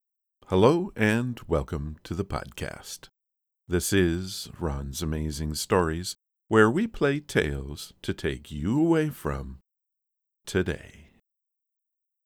The Third file is with my current Post of ( EQ - Normalize - Compress - Normalize) I ran my macro right after the DE-Ess, so it includes that as well.